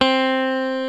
FENDER STRAT 5.wav